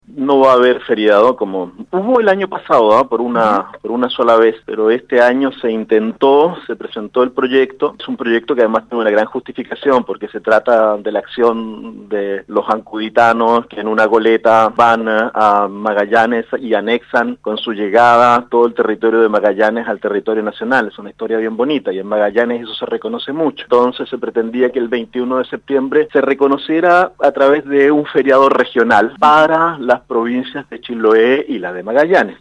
Al respecto, el diputado Gabriel Ascencio en conversación con Radio Sago manifestó profundo pesar por el cambio que se percibirá este año el cual, a su juicio, tiene una justificación bastante contundente.